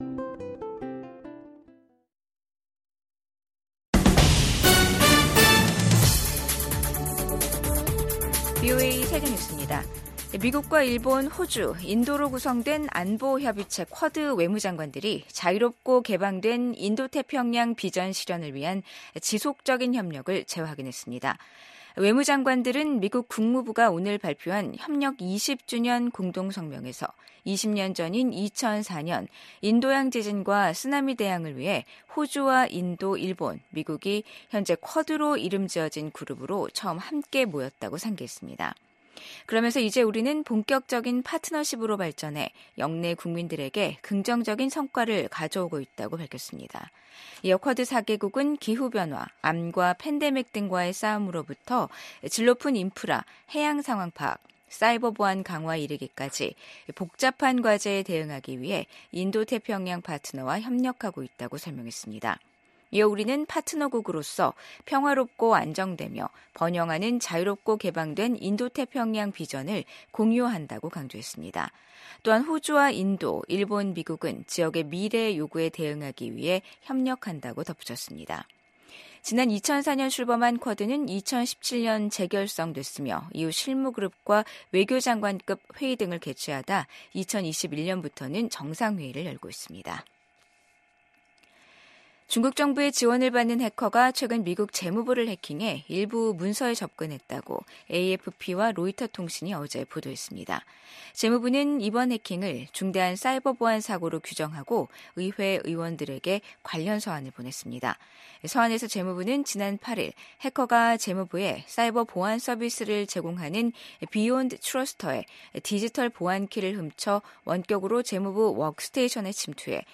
VOA 한국어 간판 뉴스 프로그램 '뉴스 투데이', 2024년 12월 31일 2부 방송입니다. 미국 국방부는 러시아에 파병된 북한군이 쿠르스크에서 벌이는 공격이 별로 효과가 없다고 평가했습니다. 북러가 한층 밀착하는 가운데 북한과 중국은 수교 75주년을 맞아 올해 선포했던 양국 우호의 해를 폐막식도 하지 못한 채 마무리하면서 두 나라의 소원해진 관계를 재차 드러냈습니다. 미국의 한반도 전문가들은 한국의 정치적 위기가 심화했다고 진단했습니다.